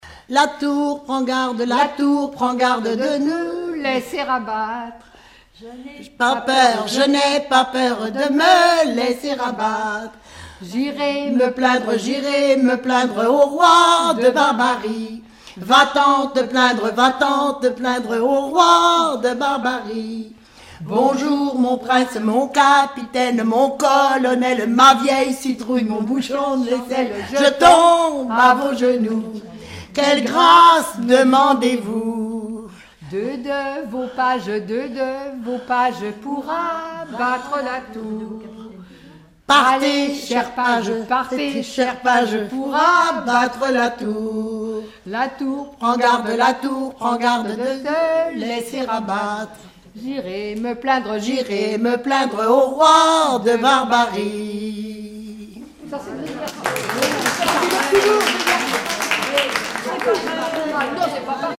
rondes enfantines
Regroupement de chanteurs du canton
Pièce musicale inédite